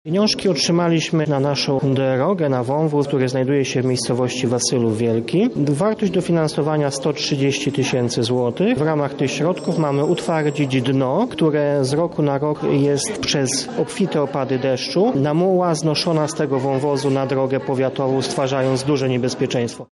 – mówi Łukasz Kłębek, wójt gminy Ulhówek.